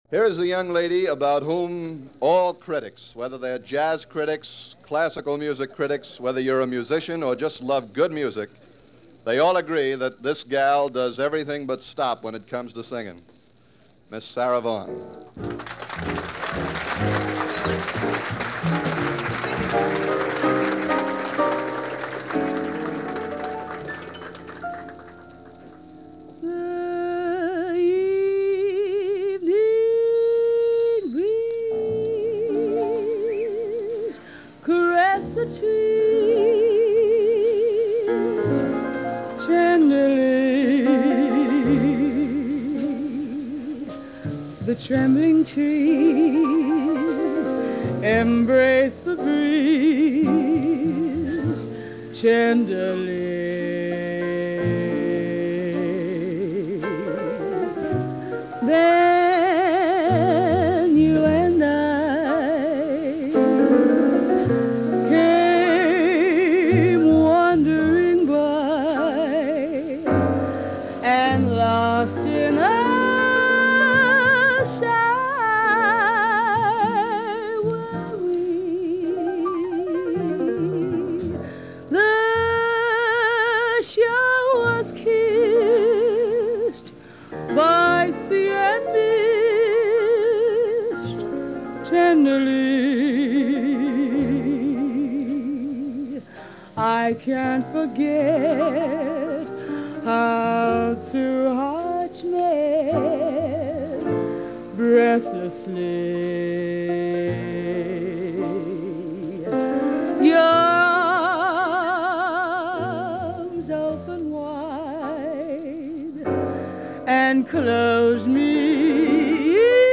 cool and sumptuous sounds